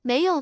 tts_result_6.wav